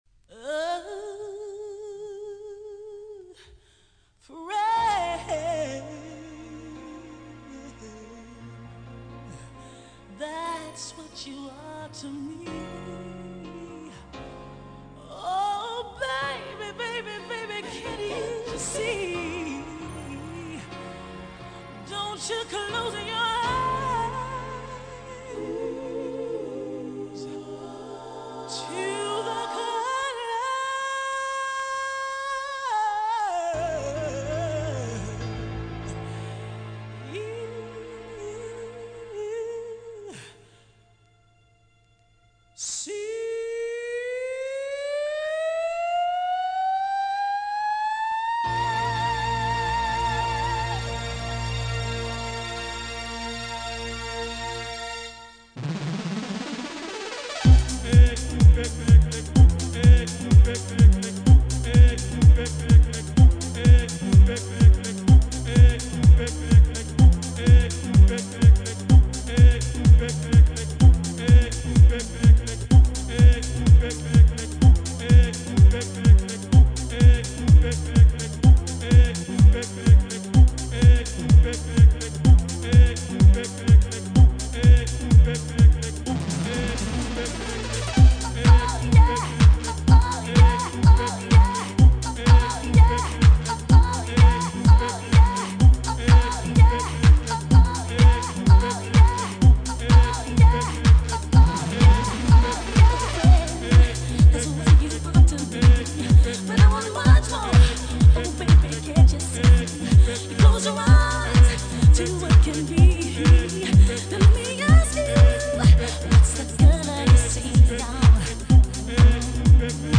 My first mix dedicated to classic rave anthems.